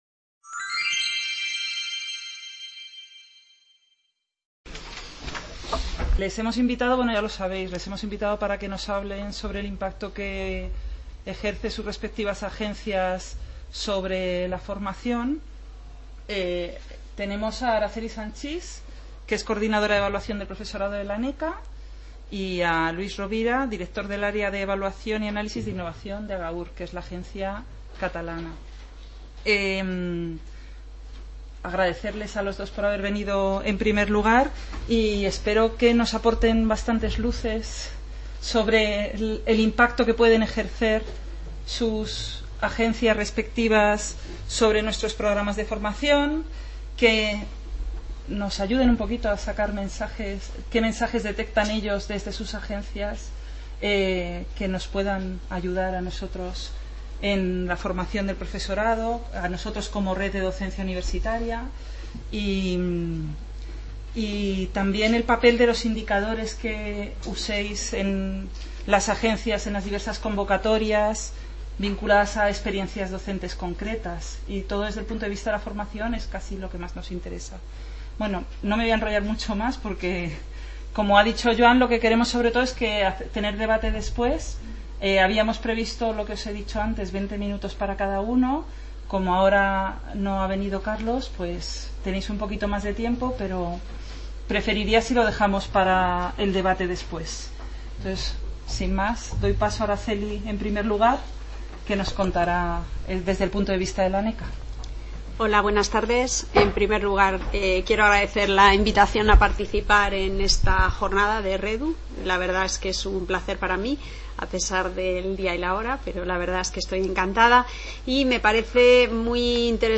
Mesa redonda